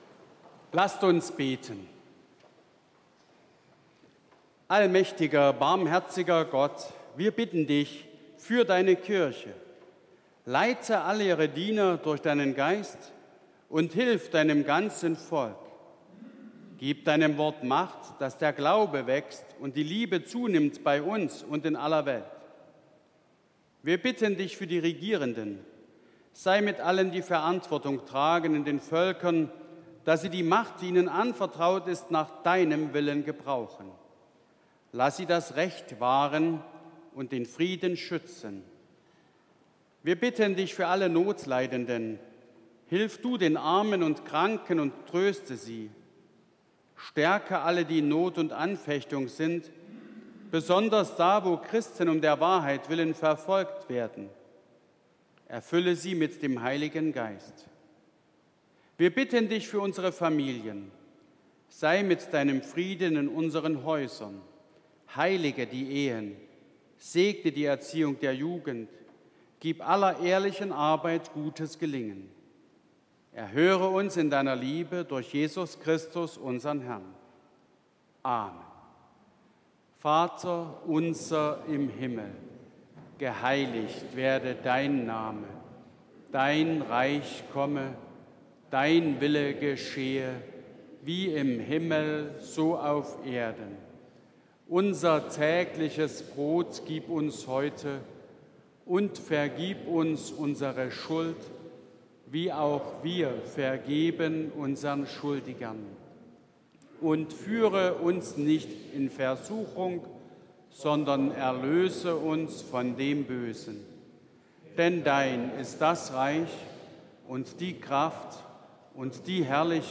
Gebet, Vaterunser, Entlassung und Segen Evangelisch-Lutherische St. Johannesgemeinde Zwickau-Planitz
Audiomitschnitt unseres Gottesdienstes am 8. Sonntag nach Trinitatis 2023